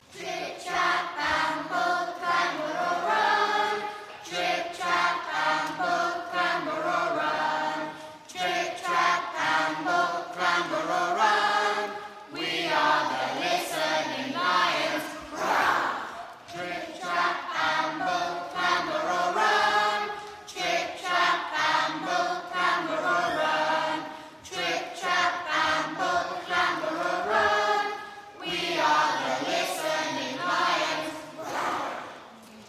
Singing History Concert 2016: Fitz Trip Trap 1